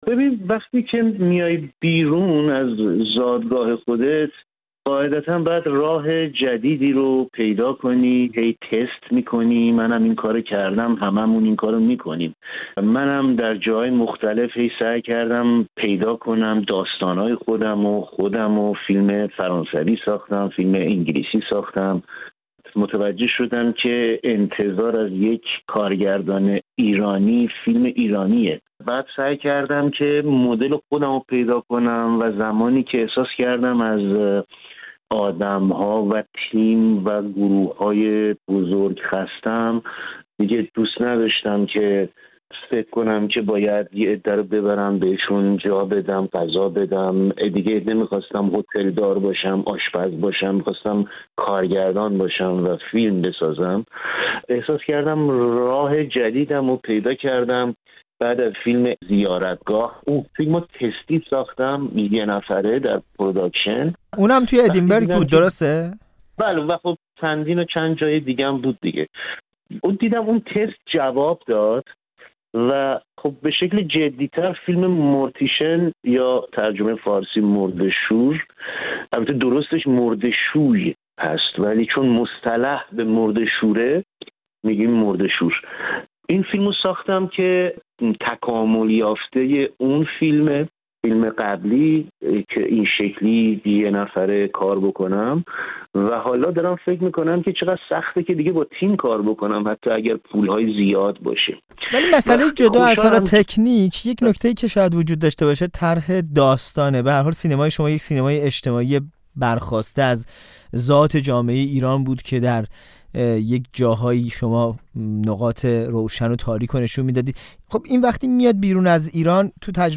گفت‌وگو با عبدالرضا کاهانی دربارۀ فیلم «مرده‌شور»